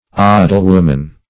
Search Result for " odalwoman" : The Collaborative International Dictionary of English v.0.48: Odalman \O"dal*man\, Odalwoman \O"dal*wom`an\, n. (Teut. Law) A man or woman having odal , or able to share in it by inheritance.